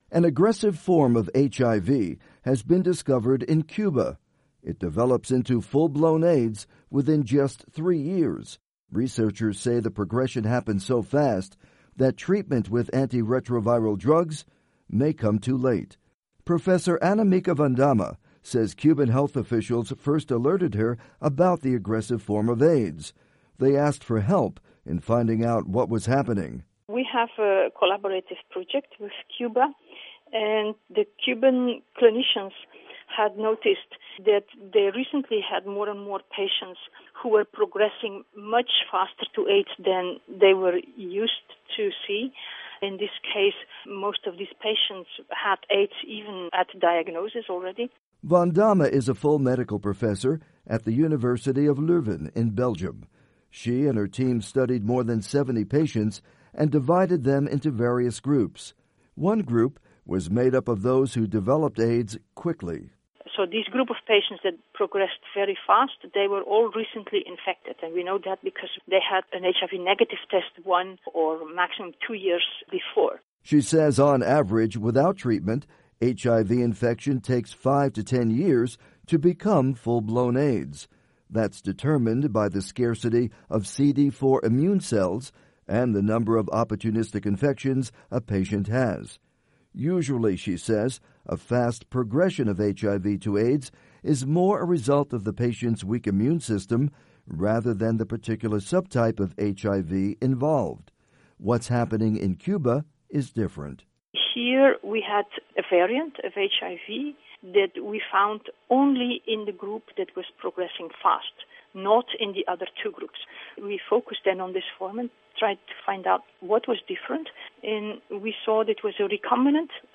report on aggressive HIV in Cuba